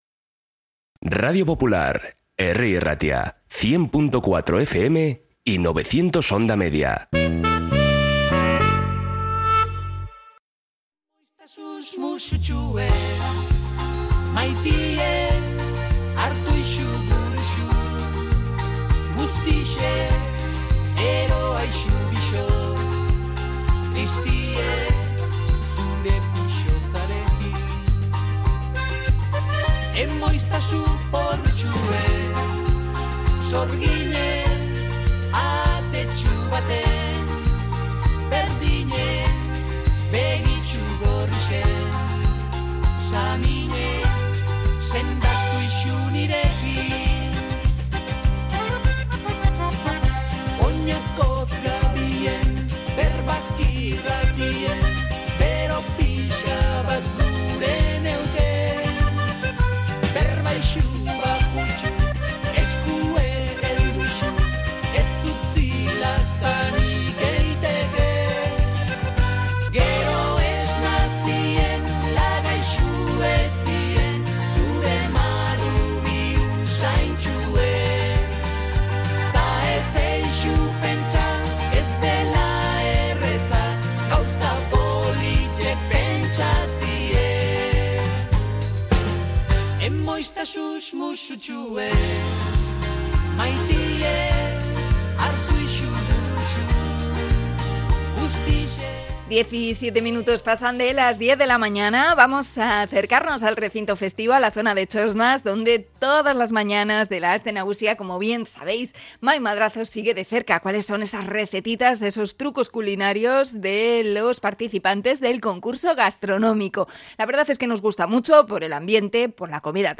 Conexión desde el concurso gastronómico de Bilboko konpartsak: hoy, sukalki